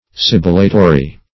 Sibilatory \Sib"i*la*to*ry\, a.
sibilatory.mp3